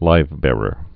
(līvbârər)